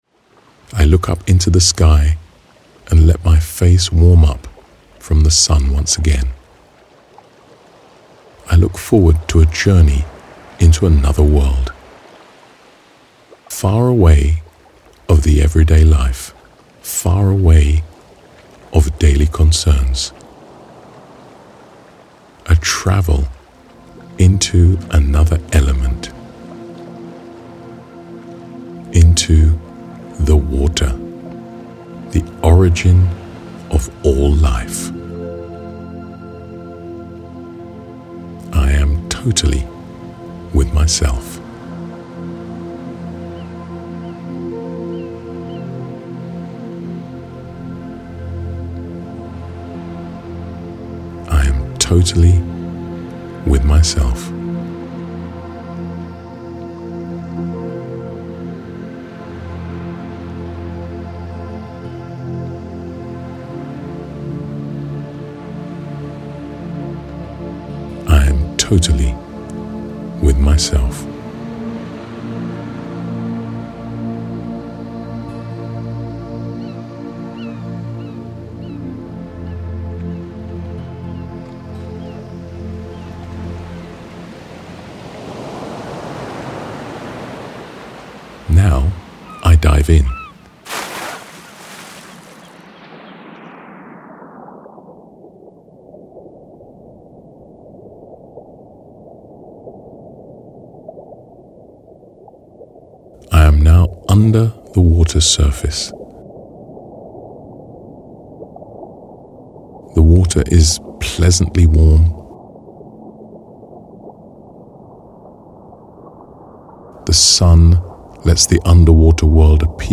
Buy audiobook:
The calm, clear voice and the spatial, gentle sounds will help you to quickly immerse yourself in a state of deep relaxation.